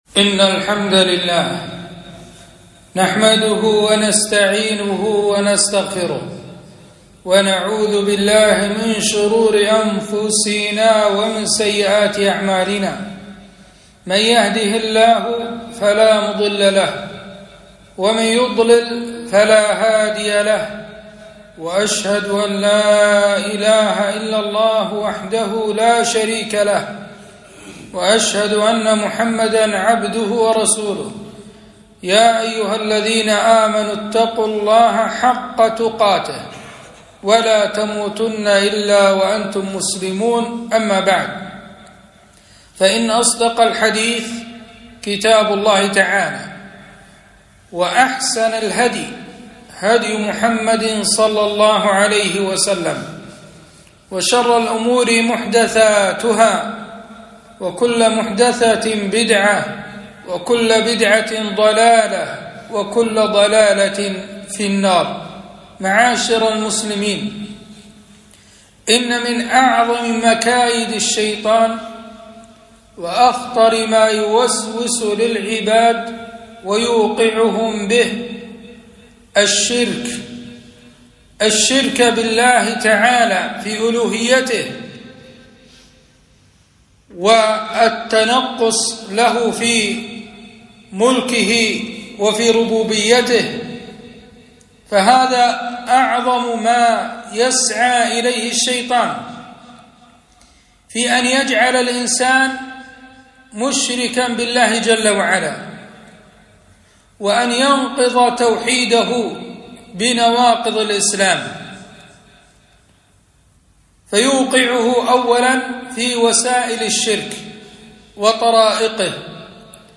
خطبة - أحكام المقابر في الإسلام